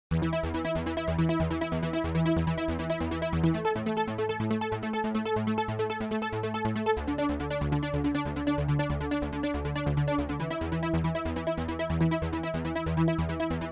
快速Arp
描述：舞蹈合成器琶音
标签： 140 bpm Dance Loops Synth Loops 2.31 MB wav Key : Unknown
声道立体声